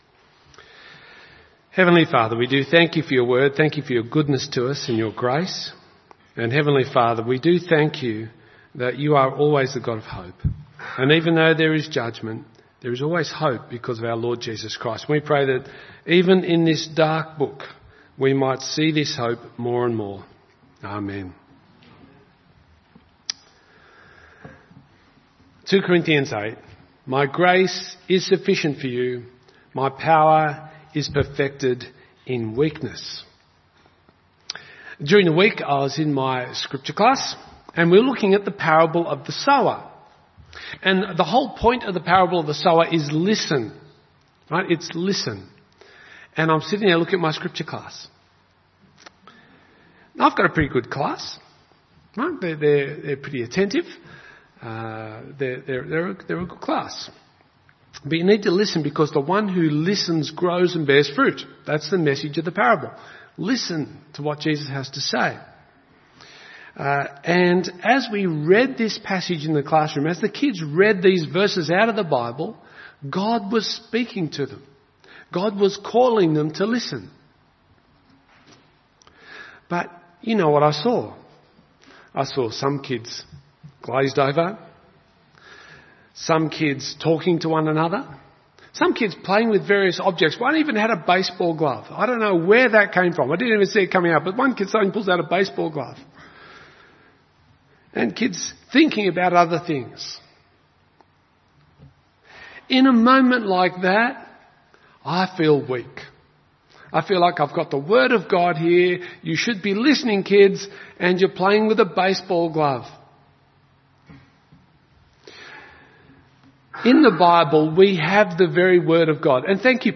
Jeremiah-1-sermon.mp3